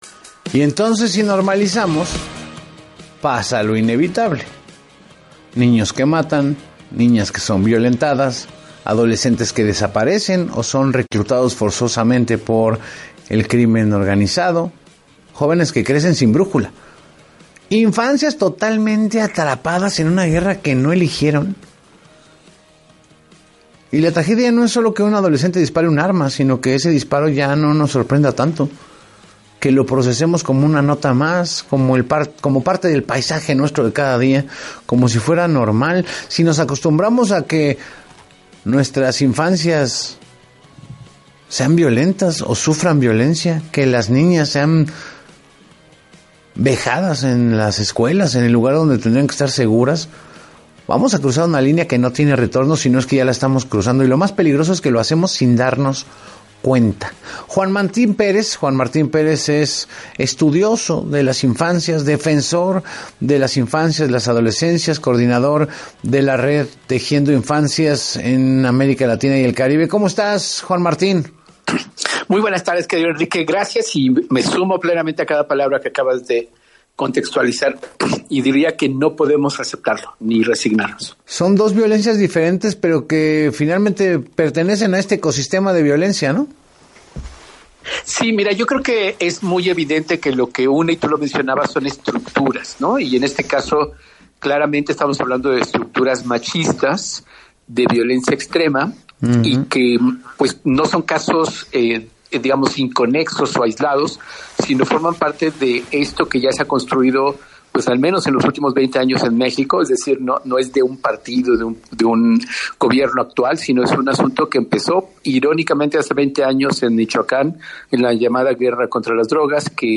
En “Así Las Cosas con Enrique Hernández Alcázar”, el especialista enfatizó que la sociedad no puede resignarse ante este panorama, y que el fenómeno actual es una consecuencia directa de la militarización iniciada hace veinte años en Michoacán, la cual ha permitido que armas de uso exclusivo del Ejército circulen fuera de control.